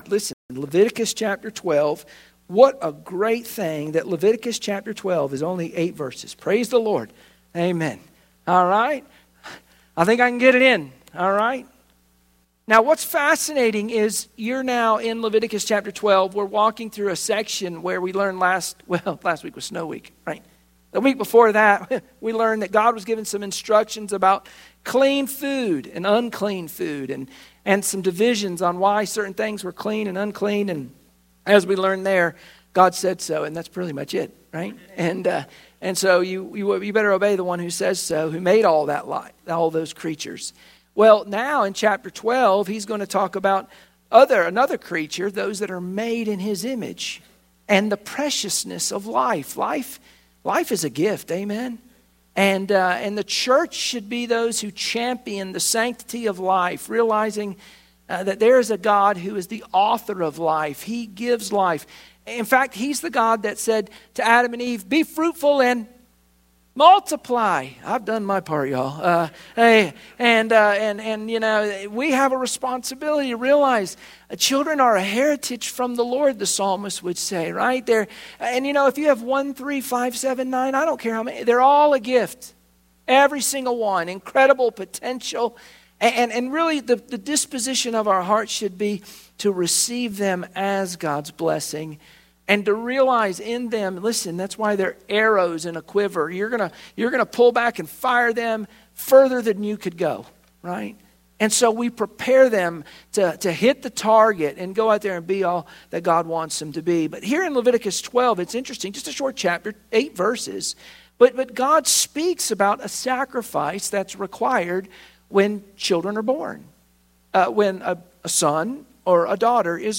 Wednesday Prayer Meeting Share this